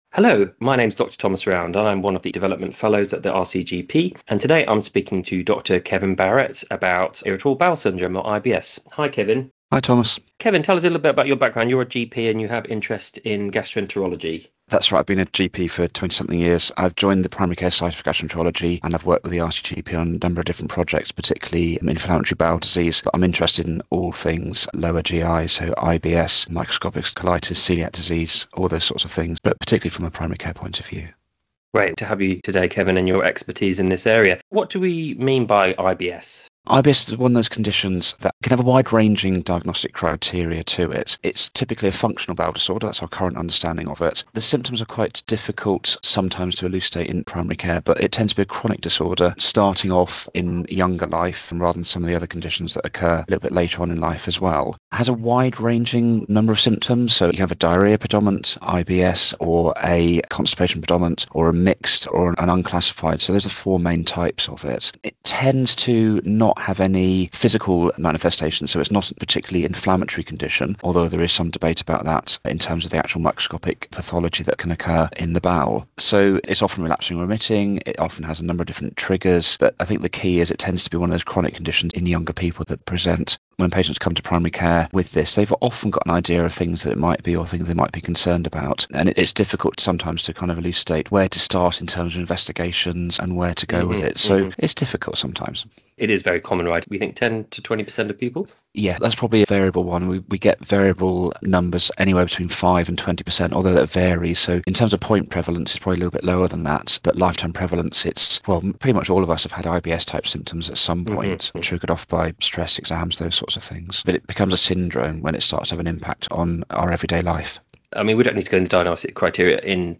This podcast is produced by the RCGP eLearning and Essential Knowledge Update teams and features discussions with clinical experts about a range of key topics in primary care.